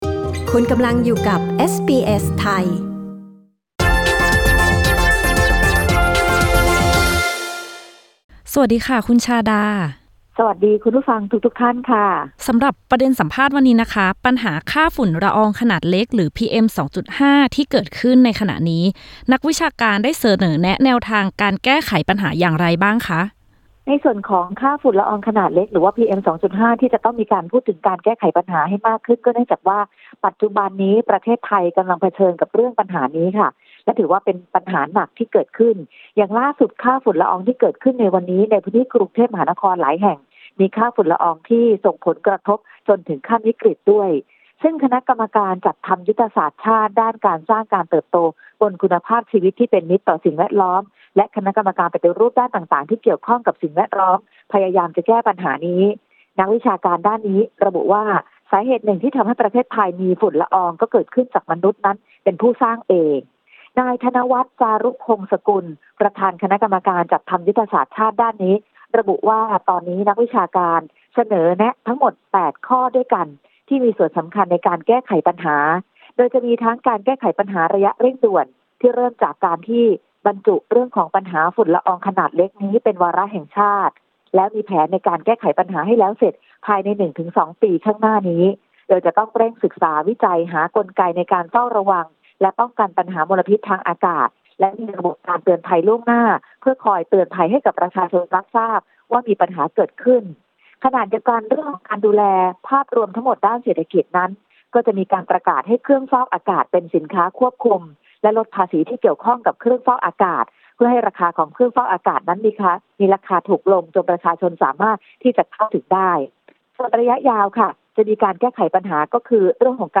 กดปุ่ม 🔊 ที่ภาพด้านบนเพื่อฟังรายงานข่าว